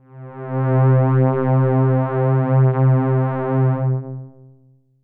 DX String C3.wav